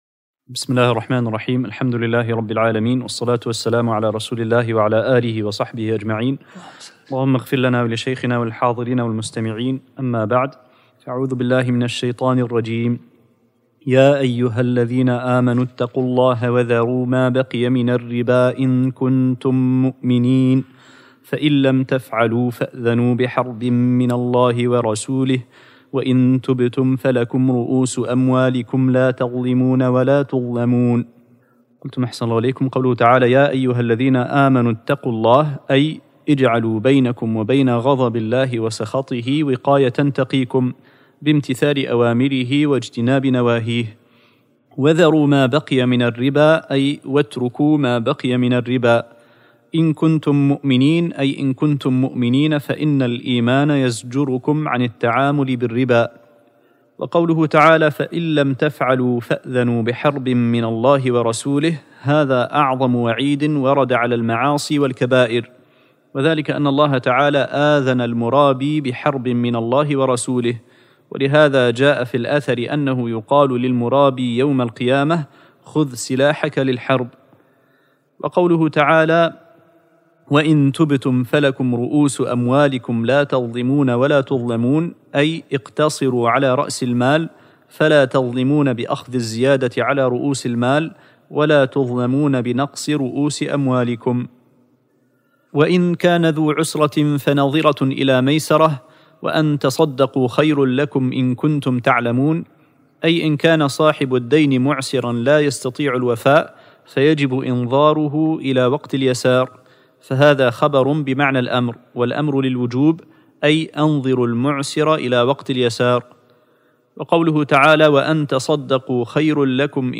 الدرس الثالث والعشرون من سورة البقرة